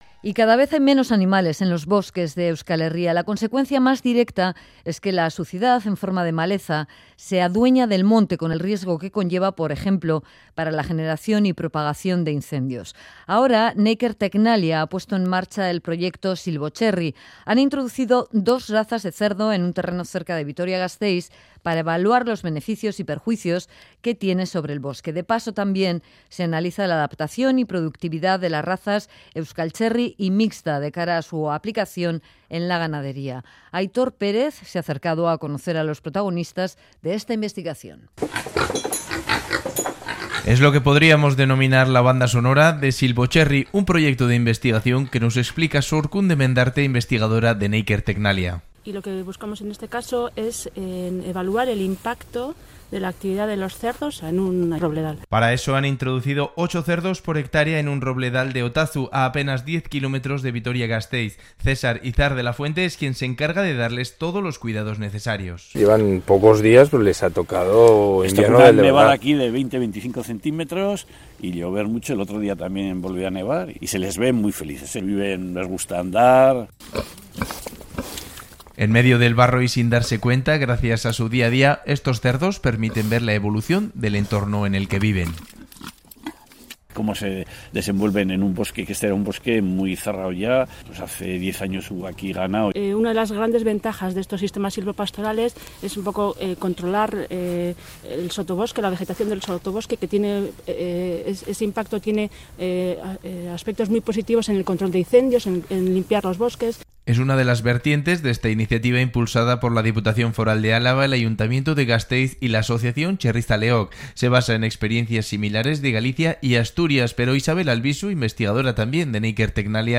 REPORTAJES